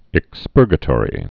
(ĭk-spûrgə-tôrē) also ex·pur·ga·to·ri·al (-tôrē-əl)